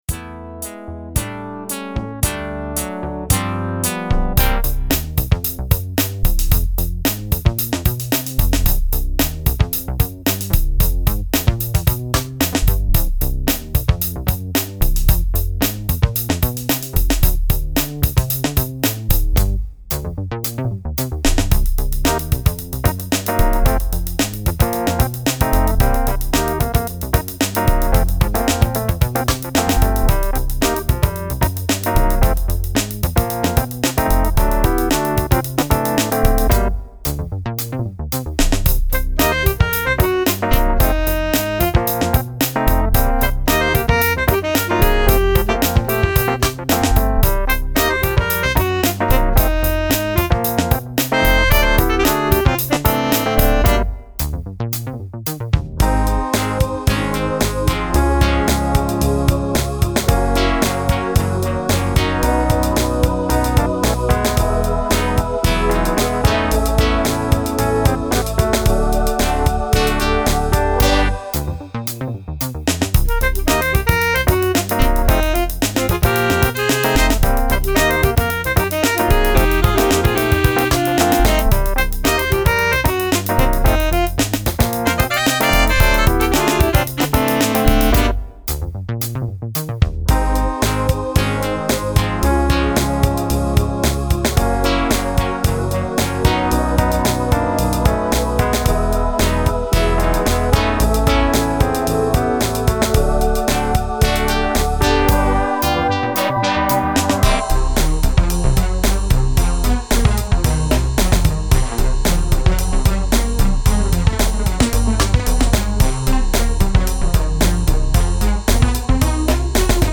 Under is a straight-ahead fun funky number.